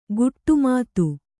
♪ guṭṭu mātu